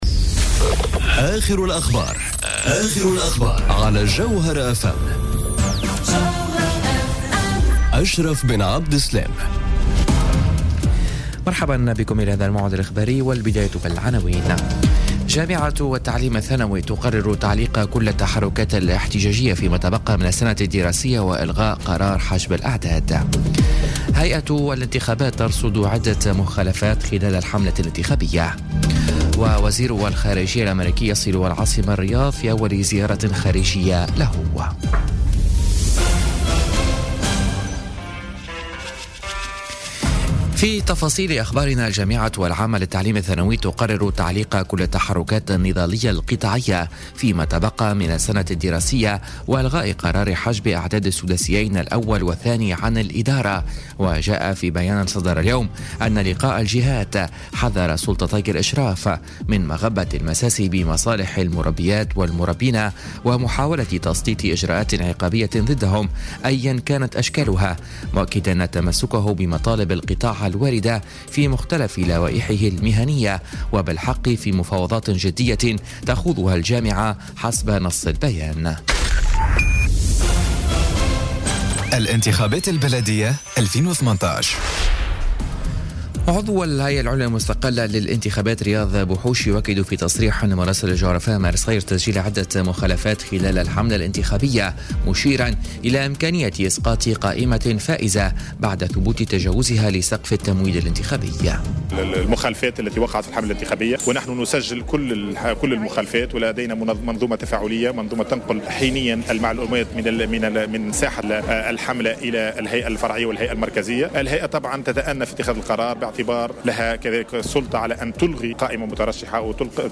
نشرة أخبار السابعة مساء ليوم السبت 28 أفريل 2018